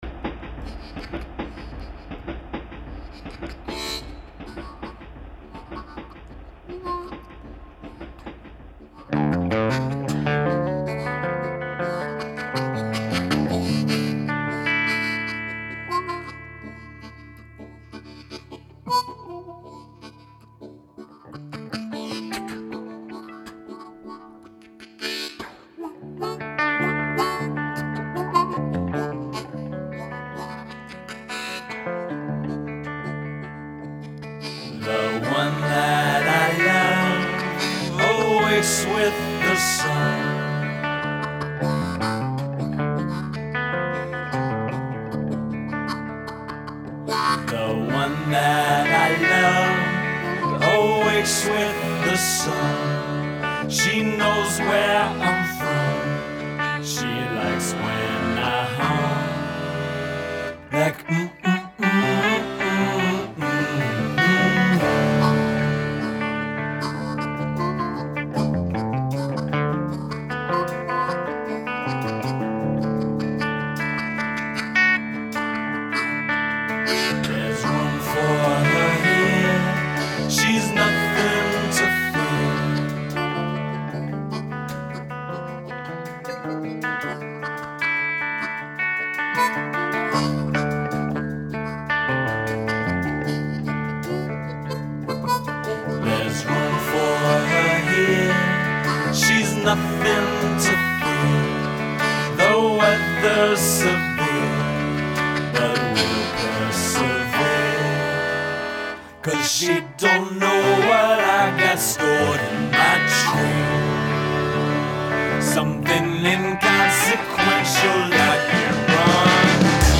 the guitarist/vocalist for the soul- and jazz-inflected trio